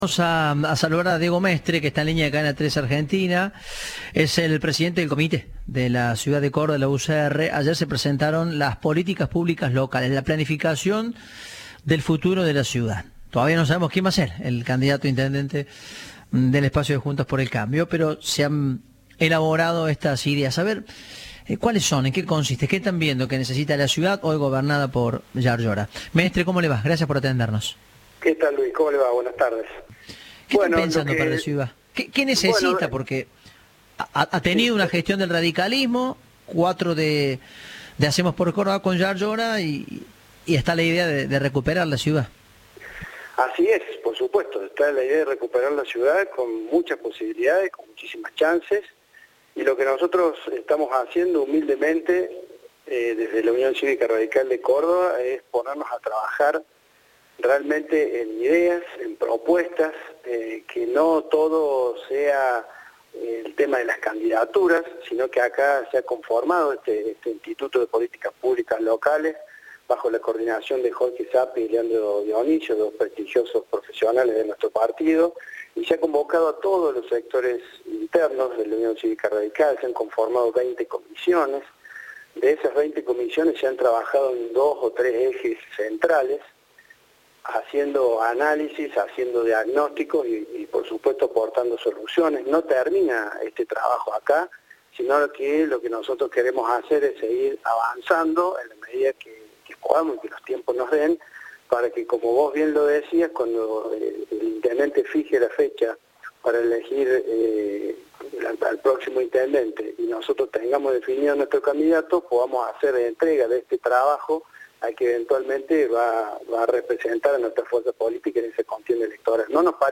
Diego Mestre, presidente del comité en la capital cordobesa, dijo a Cadena 3 que buscan cambiar el paradigma en seguridad y priorizar la salud y educación. Se lo entregarán al candidato de Juntos por el Cambio.